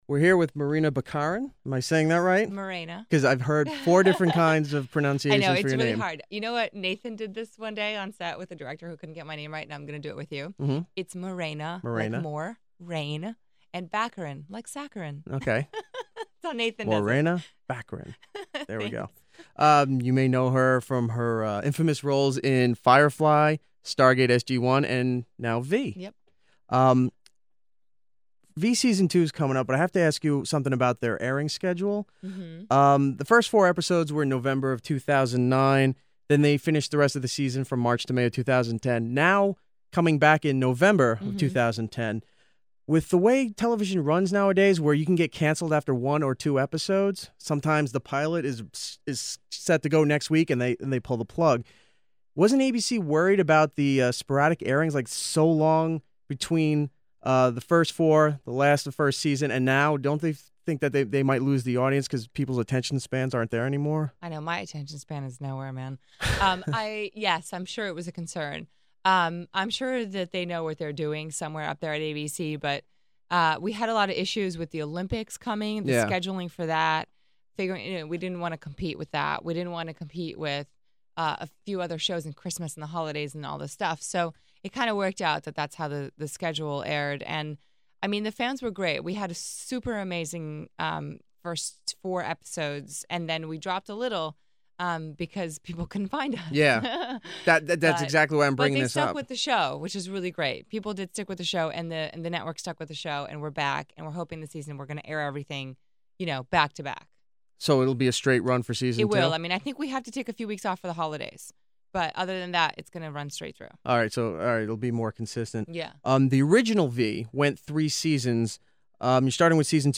Morena Baccarin Interview.mp3